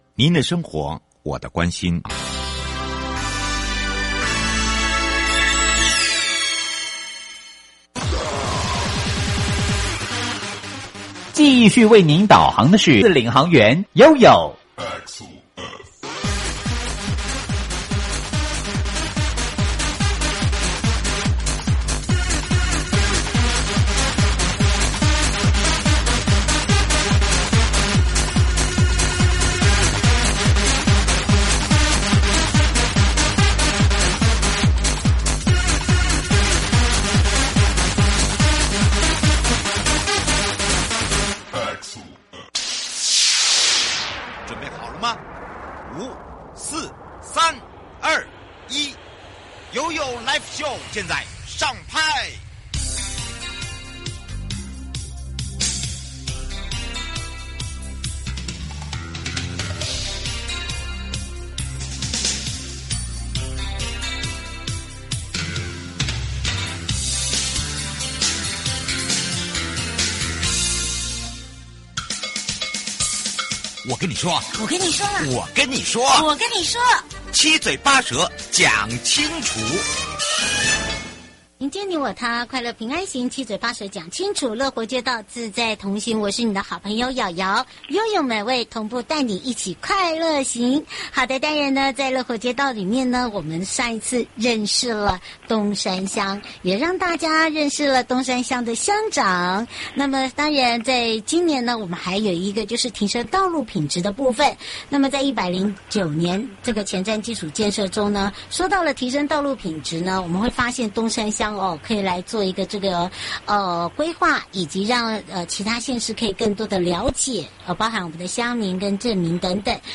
受訪者： 營建你我他 快樂平安行-冬山鄉前瞻基礎建設-提升道路品質計畫(上集) 提升道路品質計畫 2.0新一期計畫，當中跟民眾切身相關的內容有哪一些?並且跟前期的提升道路品質計畫有何差別? 剛剛講到了提升道路品質計畫(指標及內容)，冬山鄉，有做了哪些前瞻基礎建設？
節目內容： 冬山鄉公所林峻輔鄉長 提升道路品質計畫(上集)